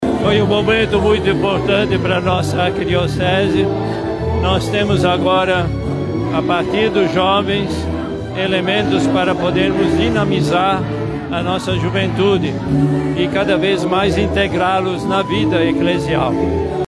Dom Leonardo ressaltou que a juventude assume agora a missão de indicar à Igreja de Manaus como deseja caminhar e viver o Evangelho de Jesus, a partir de uma linguagem jovem, atual e comprometida com a fé.